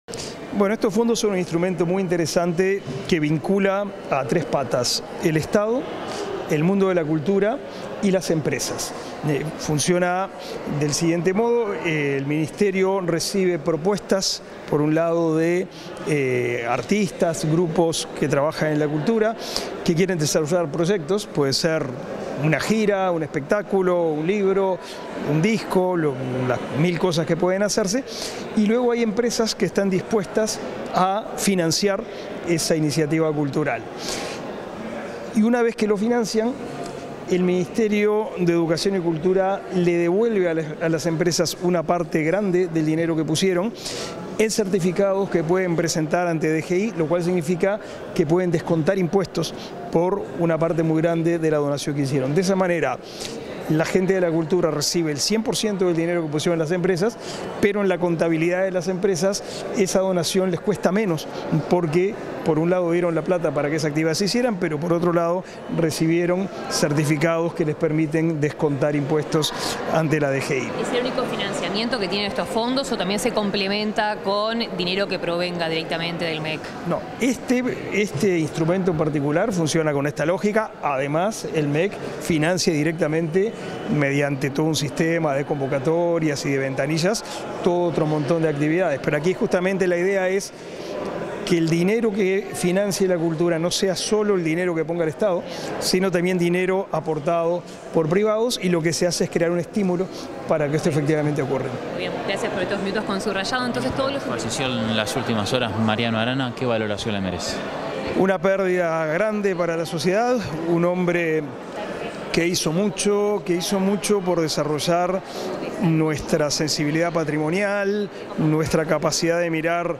Declaraciones del ministro de Educación y Cultura, Pablo da Silveira
Declaraciones del ministro de Educación y Cultura, Pablo da Silveira 05/06/2023 Compartir Facebook X Copiar enlace WhatsApp LinkedIn Tras el lanzamiento de la convocatoria de los Fondos de Incentivo Cultural, este 5 de junio, el ministro de Educación y Cultura, Pablo da Silveira, realizó declaraciones a la prensa.